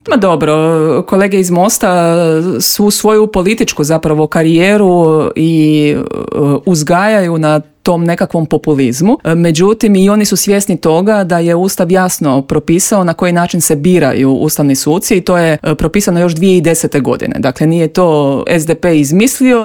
SDP-u je neprihvatljivo da se prvo glasa o opozivu premijera, a tek onda o ustavnim sucima, poručila je jutros u Intervjuu Media servisa zastupnica SDP-a Mirela Ahmetović.